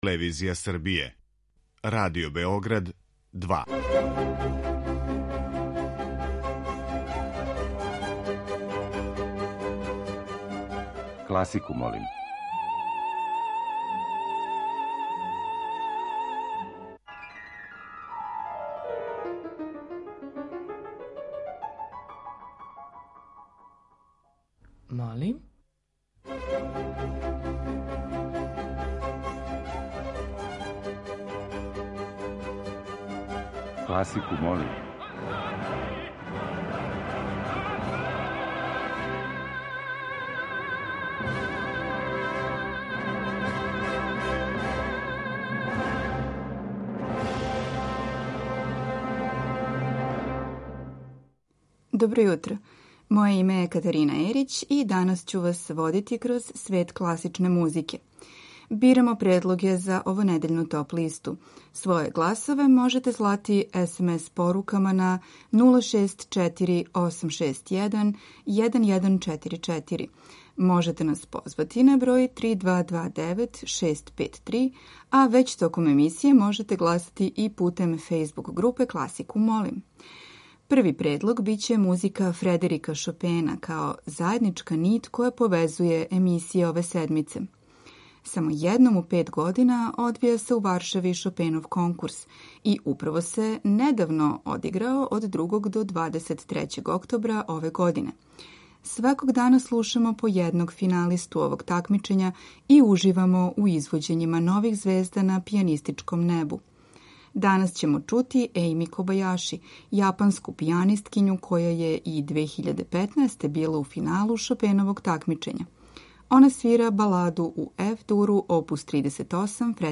Избор за топ-листу класичне музике Радио Београда 2
Снимци најбољих такмичара на последњем Шопеновом конкурсу у Варшави.